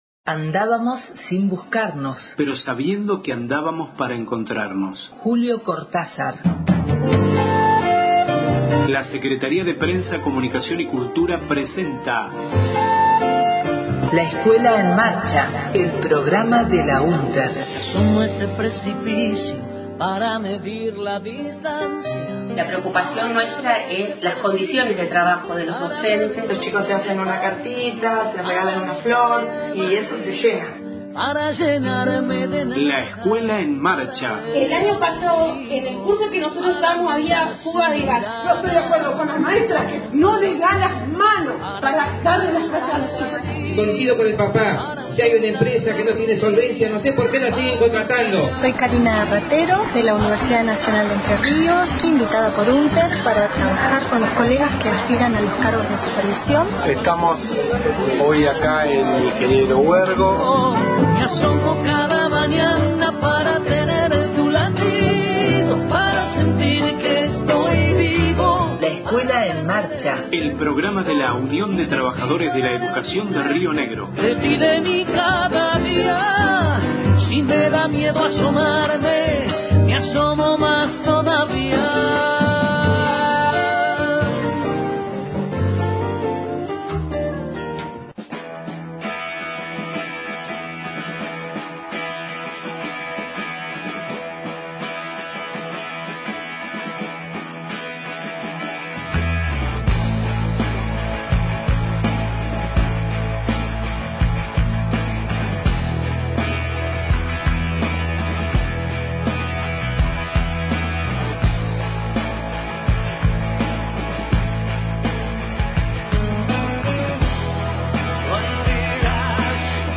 Audio en marcha realizada en Roca el 2/06/16 en el marco del paro contra las políticas de ajuste.